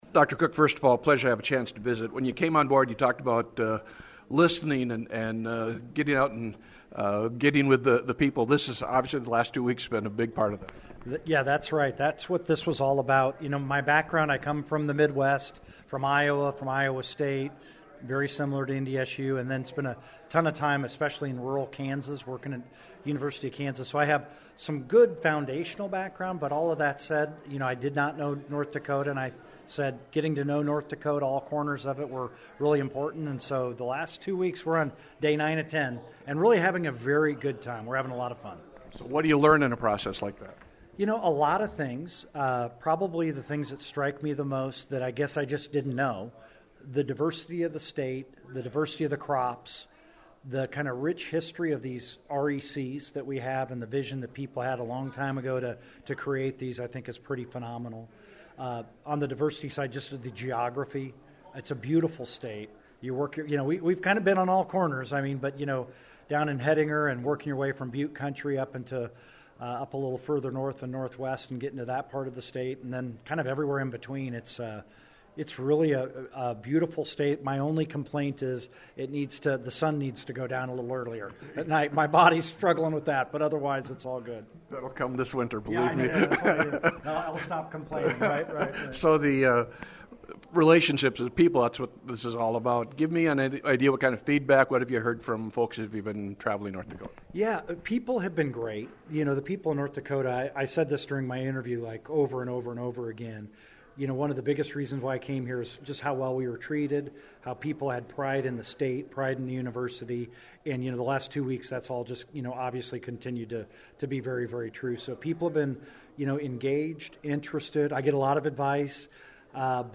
The full interview can be found online.
0721-David-Cook.mp3